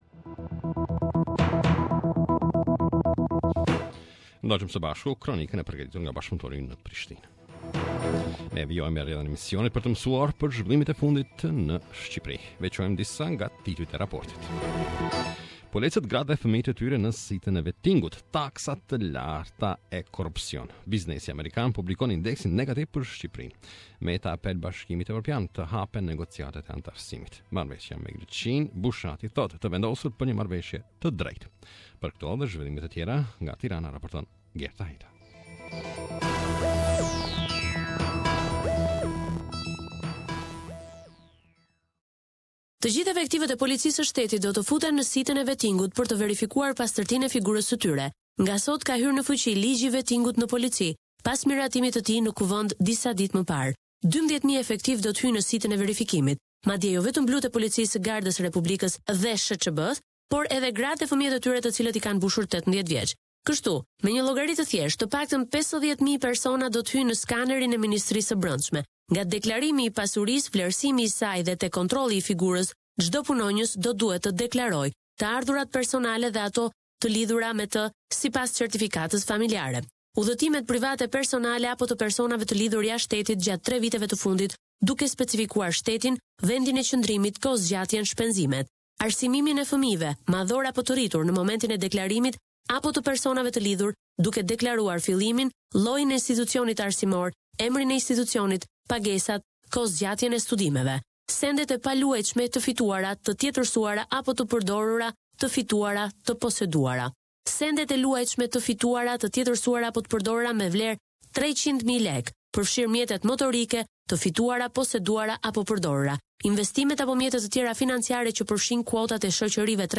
(SBS Albanian) Raporti rreth zhvillimeve me te fundit ne Shqiperi.